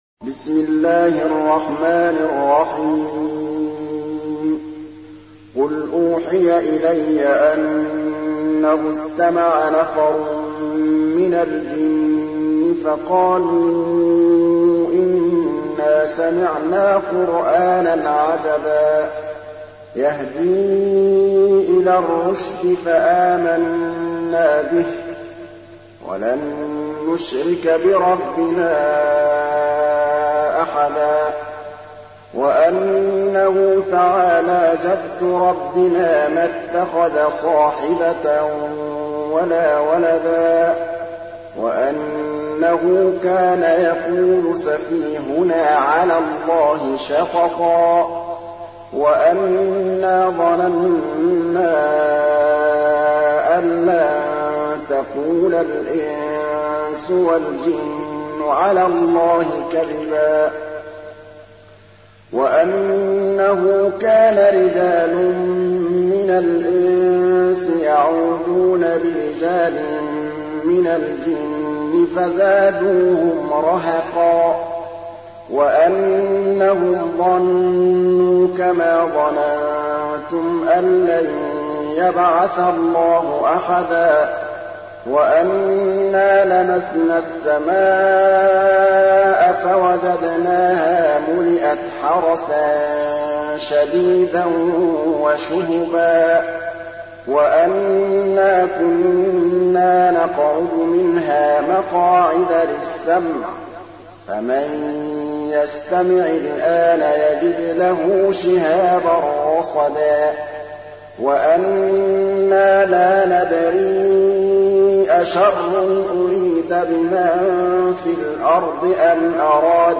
تحميل : 72. سورة الجن / القارئ محمود الطبلاوي / القرآن الكريم / موقع يا حسين